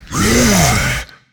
burer_attack_3.ogg